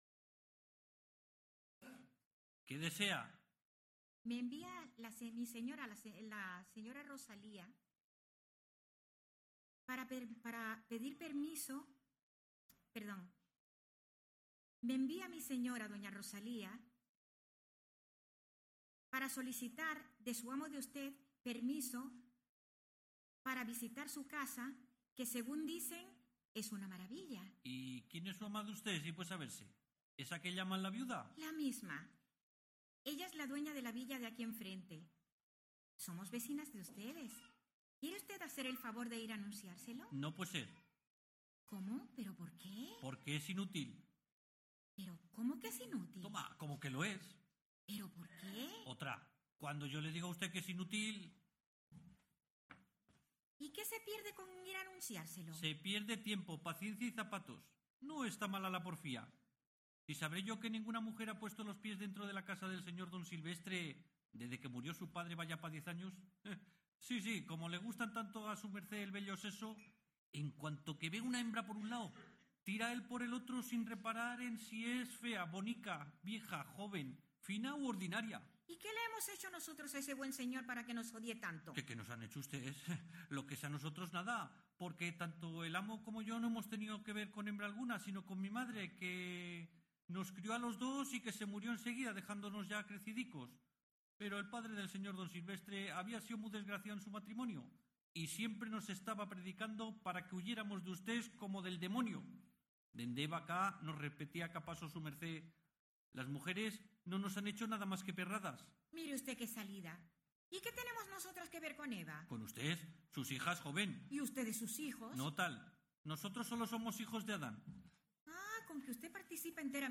“Un ente singular” formato MP3 audio(3,64 MB), de Ramón de Navarrete. Ha destacado el jurado que se trata de “un trabajo muy bueno, con un ritmo de lectura acertado y las divertidas réplicas están muy bien colocadas; con una edición de grabación
meticulosa y esmerada con una ambientación sonora muy conseguida” formato MP3 audio(0,34 MB).